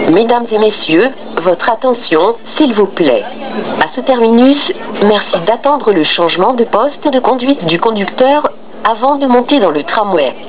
Un message est donc diffusé dans la rame, au terminus, pour demander d'attendre le changement de "poste de conduite du conducteur" avant de monter. J'ai aussi entendu ce message diffusé par les haut-parleurs en station, à E. Grappe.